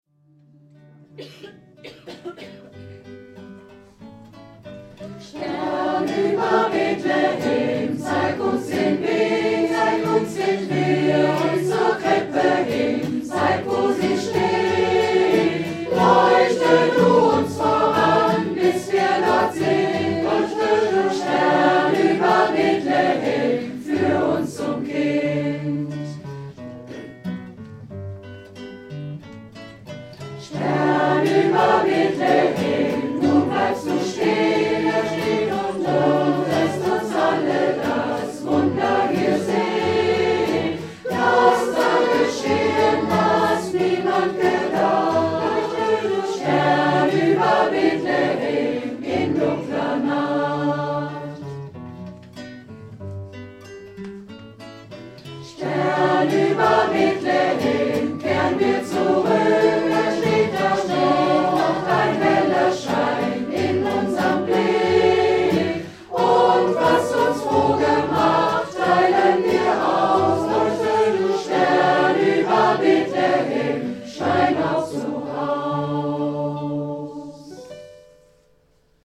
Die folgenden Stücke wurden in einem 3 1/2-stündigem Chorvergnügen erarbeitet: